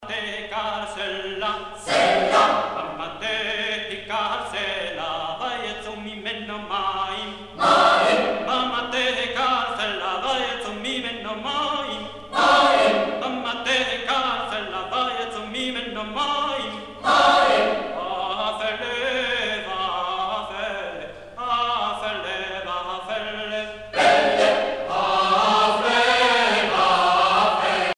Chansons douces et chansons d'amour
Pièce musicale éditée